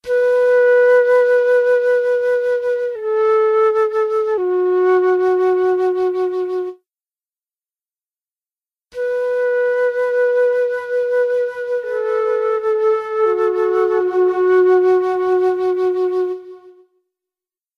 Delay
En cambio en el siguiente se consigue un sonido más perfeccionado y más agradable.
multitapflute.wav